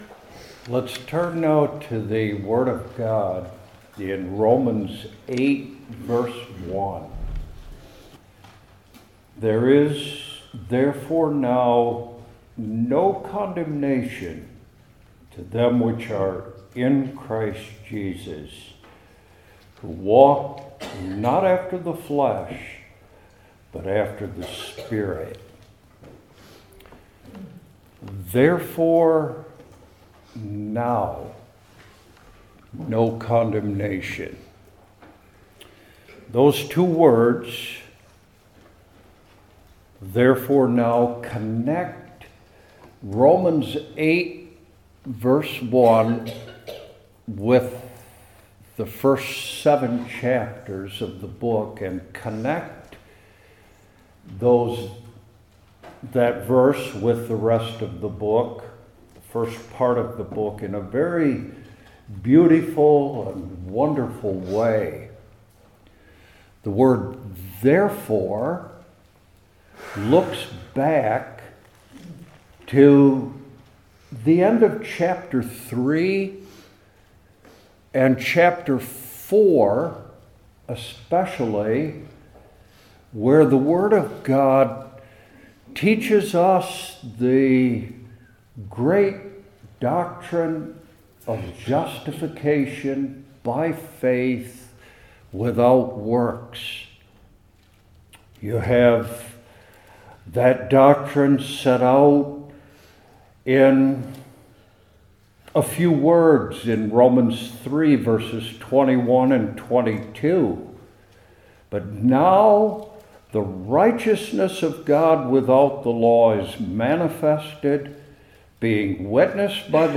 New Testament Individual Sermons I. The Wonder II.